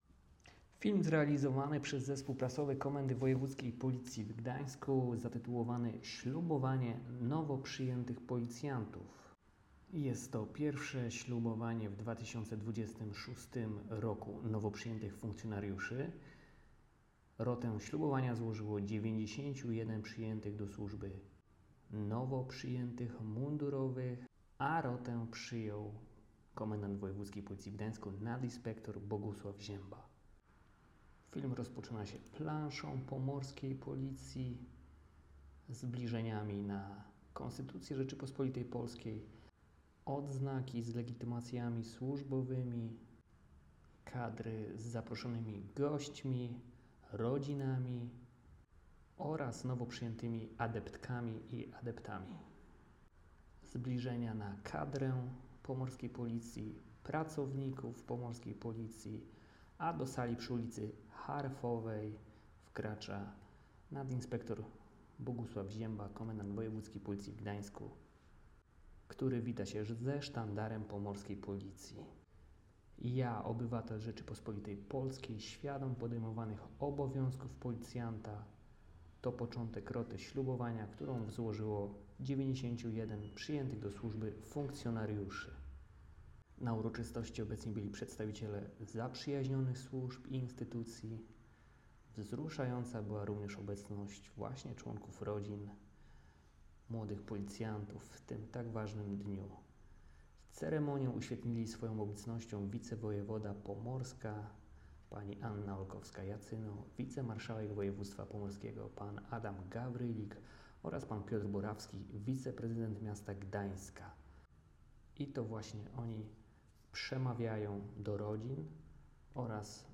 Nagranie audio audiodyskrypcja__2_.m4a